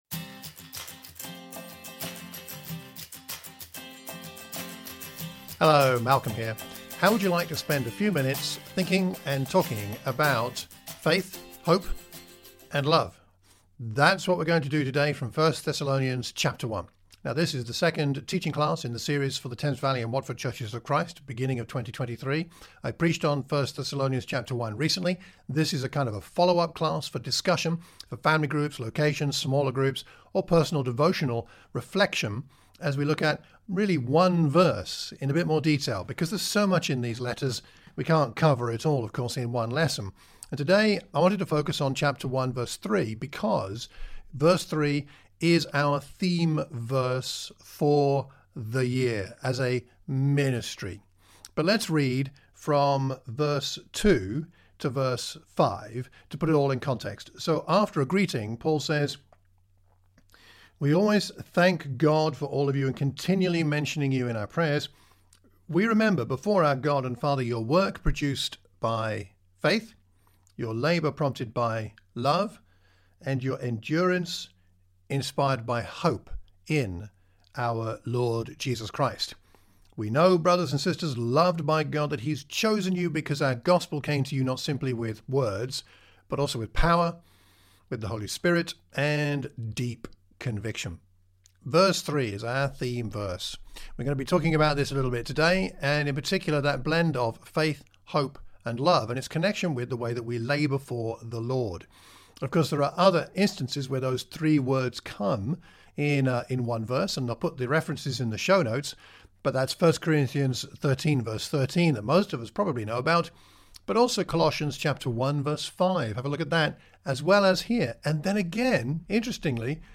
1 Thessalonians | Teaching Class - Chapter 1